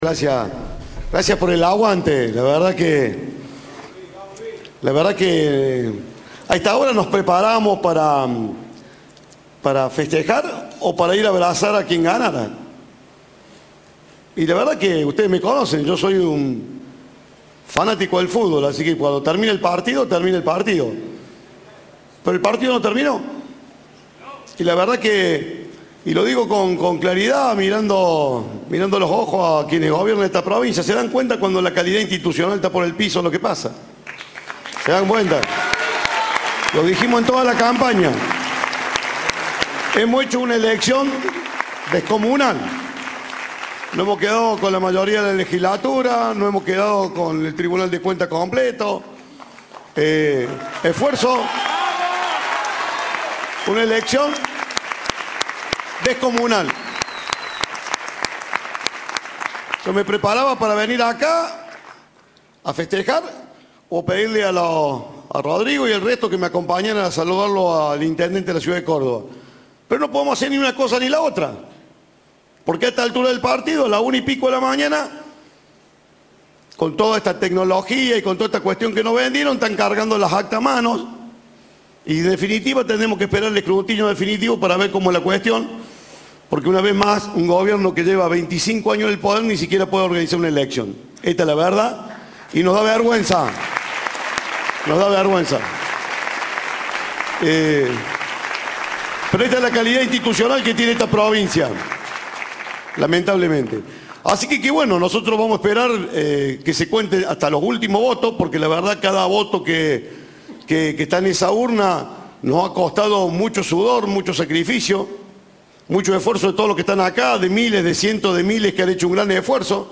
En el búnker de la coalición opositora montado en el Espacio Quality, Juez aseguró que “el partido no terminó” y remarcó que se deberá aguardar que “se cuente hasta el último voto”.
En diálogo con Cadena 3 tras bajarse el escenario, Juez calificó de “sospechoso, raro y difícil” lo sucedido en estos comicios provinciales.